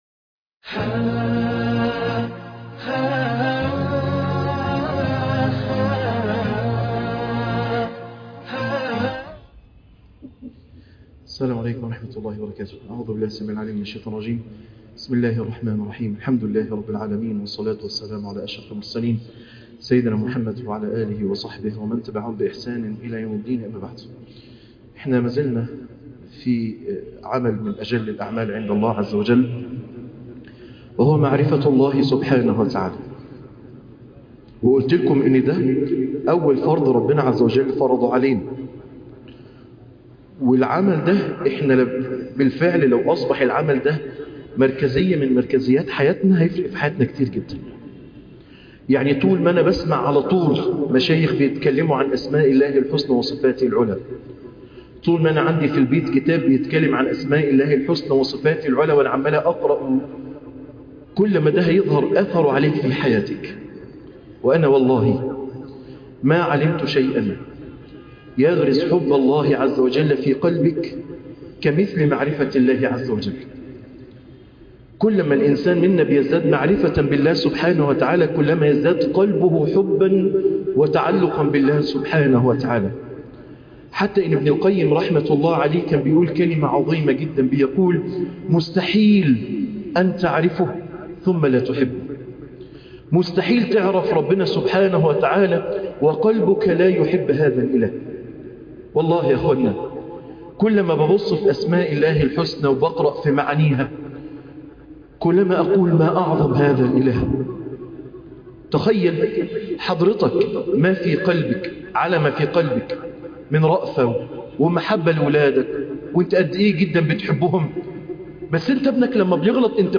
ليلة القدر - عبودية الصالحين - درس التروايح